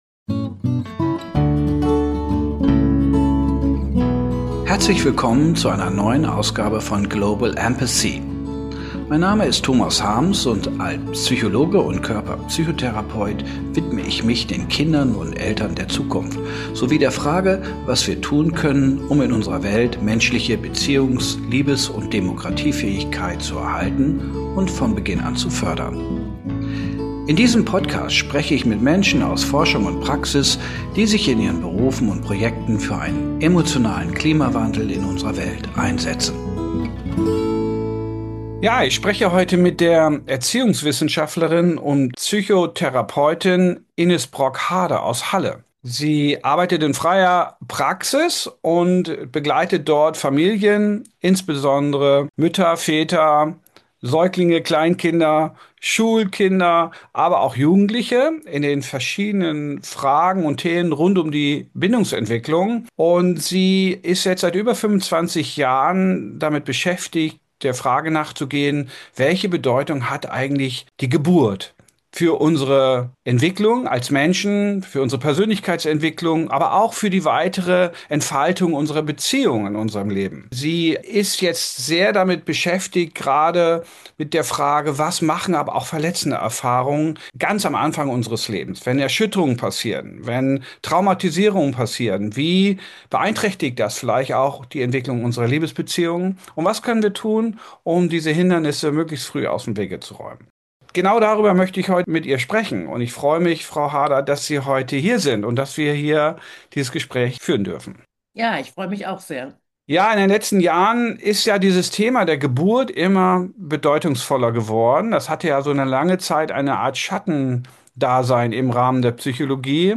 Beschreibung vor 2 Monaten Wie beeinflussen traumatische Geburtserfahrungen von Müttern und Vätern den Aufbau einer sicheren Bindung zum Kind? Was können betroffene Eltern tun, um die Hindernisse eines gelingenden Miteinanders mit ihren Kindern aus dem Wege zu räumen? In dieser Folge spreche ich mit der Kinder- und Jugendpsychotherapeutin